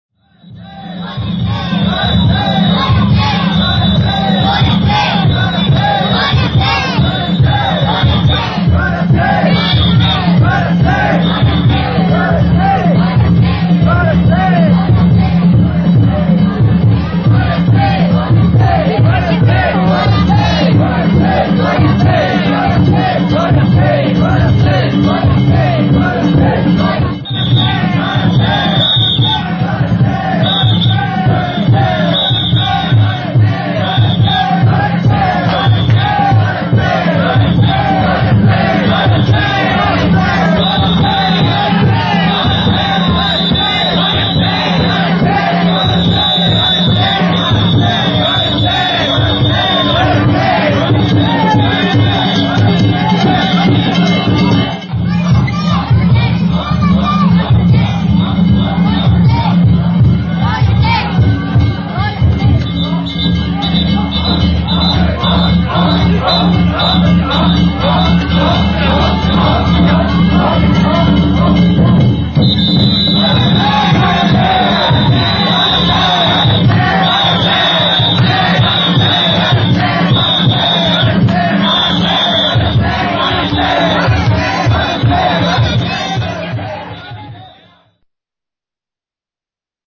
平成２８年３月１２日、羽曳野市誉田地区の馬場町地車お披露目曳行を見に行ってきました。
こりゃせー♪こりゃせー♪
お囃子の曲調が変わり、合わせて盛り上がる曳き手